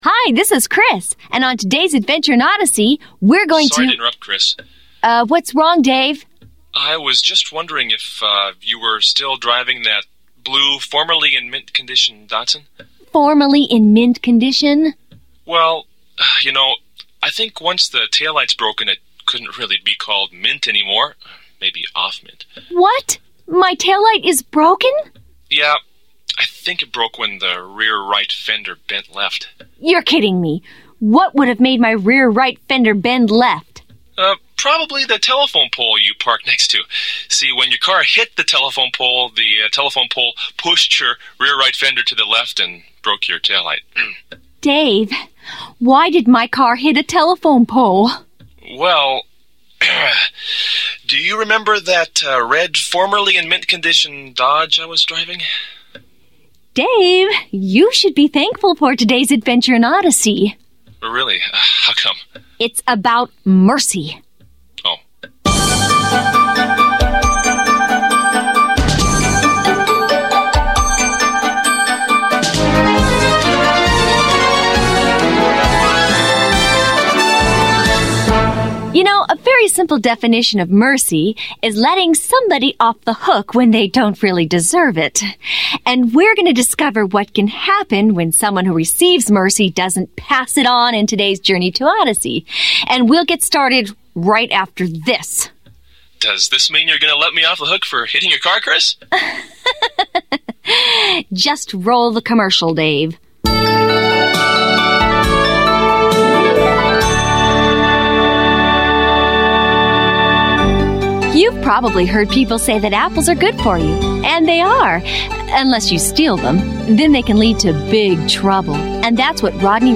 Created for ages 8-12 but enjoyed by the whole family, Adventures in Odyssey presents original audio stories brought to life by actors who make you feel like part of the experience. These fictional, character-building dramas are created by an award-winning team that uses storytelling to teach lasting truths.